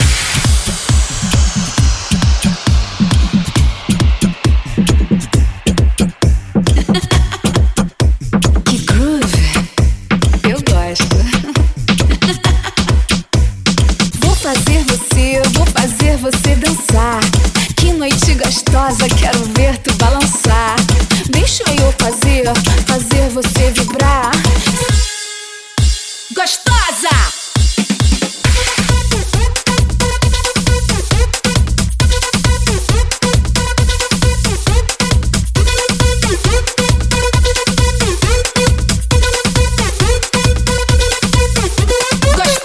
Genere: latin pop, latin house, latin tribal, bachata